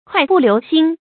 快步流星 注音： ㄎㄨㄞˋ ㄅㄨˋ ㄌㄧㄨˊ ㄒㄧㄥ 讀音讀法： 意思解釋： 形容步子跨得大，走得快。